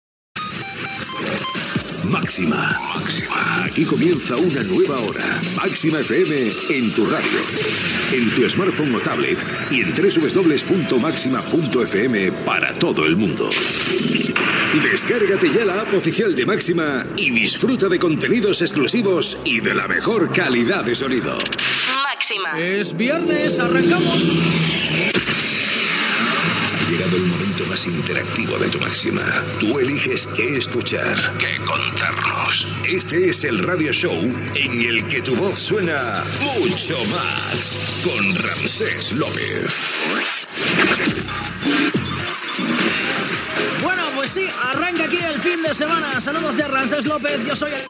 Aplicació oficial de l'emissora, careta del programa i salutació
Musical